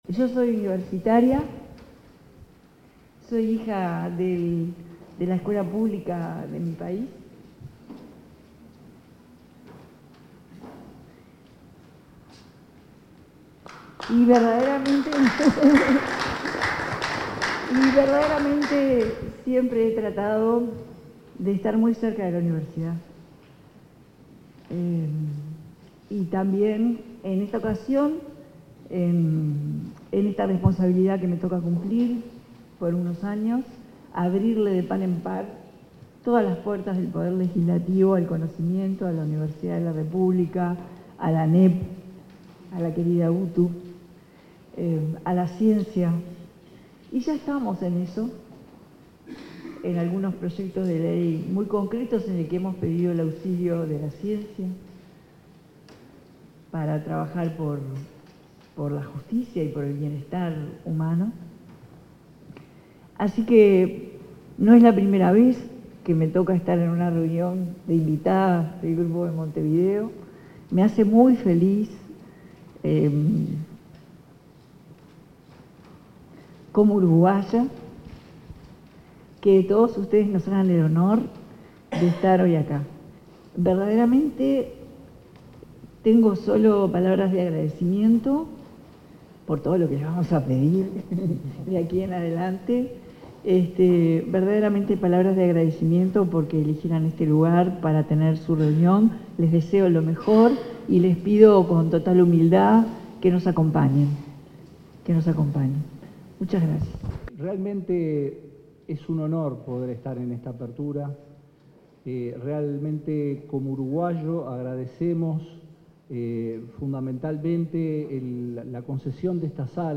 Palabras de las autoridades en firma de convenios entre universidades de la región 01/07/2025 Compartir Facebook X Copiar enlace WhatsApp LinkedIn En el acto de firma de convenios entre instituciones públicas de enseñanza de Uruguay y la región, se pronunciaron la presidenta de la República en ejercicio, Carolina Cosse; el rector interino de la Universidad de la República, Álvaro Mombrú; el ministro de Turismo, Pablo Menoni, y la directora general de Educación Técnico Profesional (DGETP-UTU), Virginia Verderese.
Oratorias.mp3